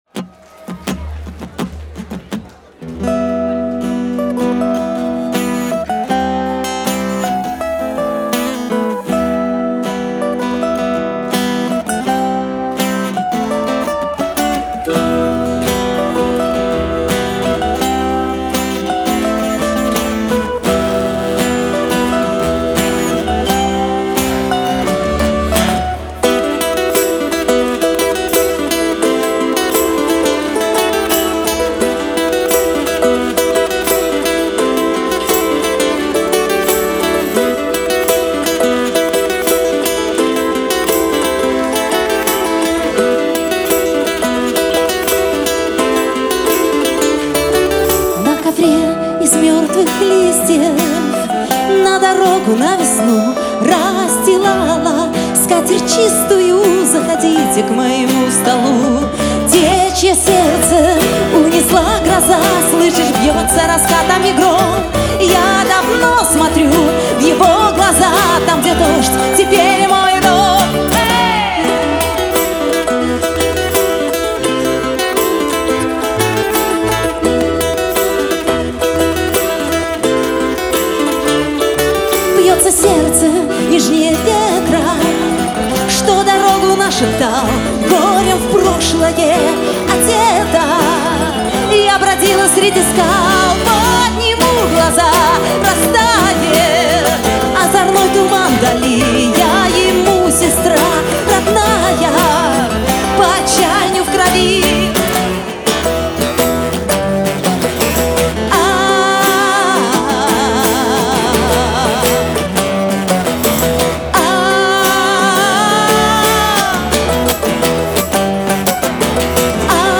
вокал
акустическая гитара
балалайка
перкуссия, ударные
аккордеон
Треки с акустического концертного альбома